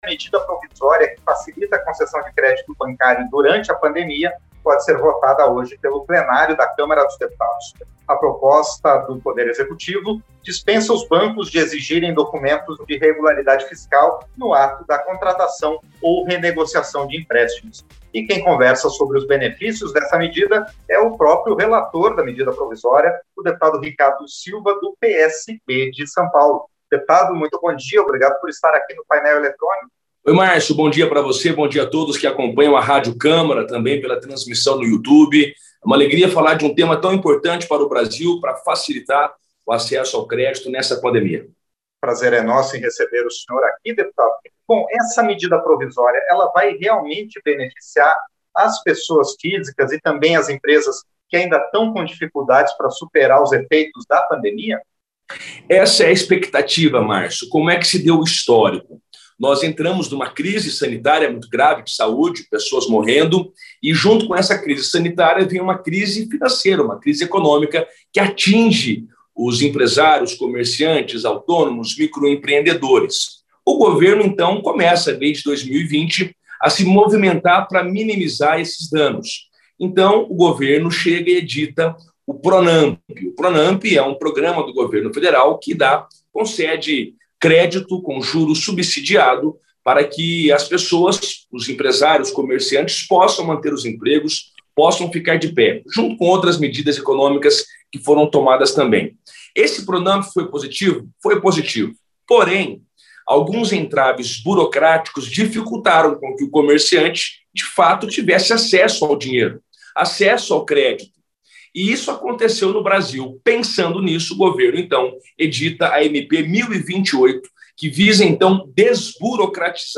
• Entrevista - Dep. Ricardo Silva (PSB-SP)
Programa ao vivo com reportagens, entrevistas sobre temas relacionados à Câmara dos Deputados, e o que vai ser destaque durante a semana.